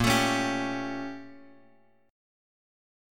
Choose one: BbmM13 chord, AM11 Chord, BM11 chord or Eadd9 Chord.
BbmM13 chord